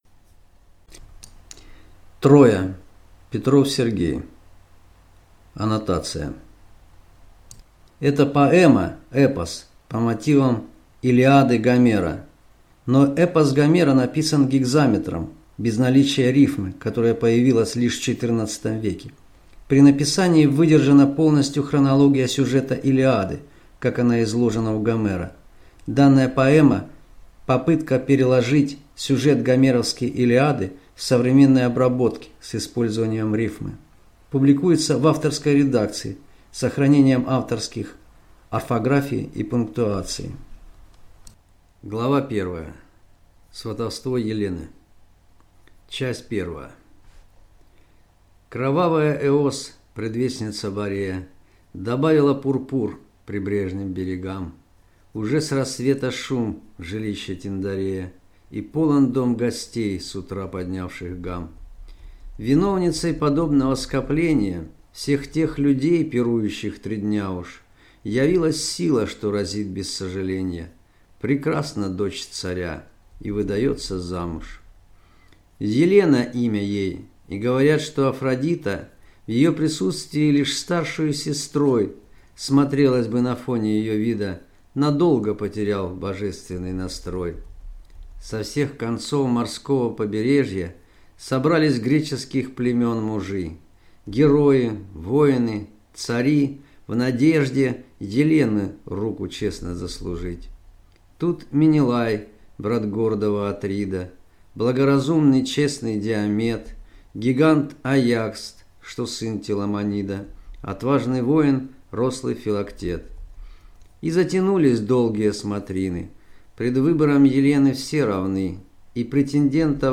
Аудиокнига Троя | Библиотека аудиокниг